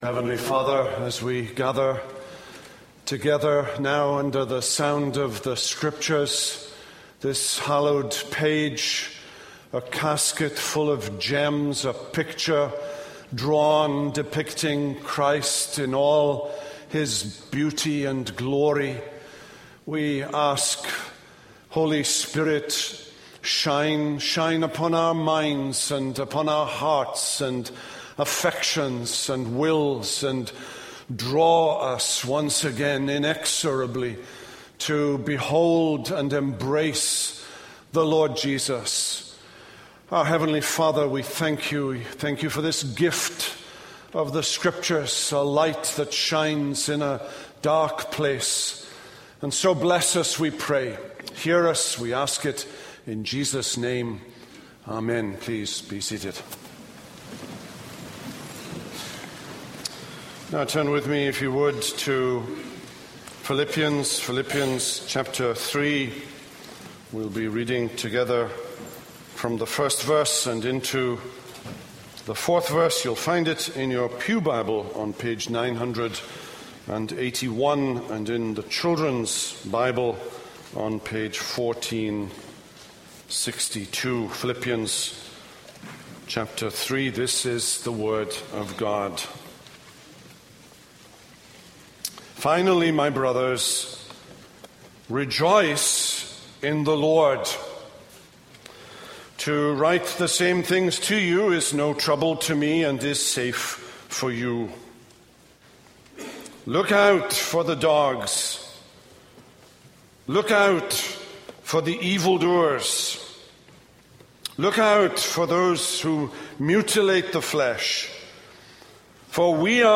This is a sermon on Philippians 3:1-4a.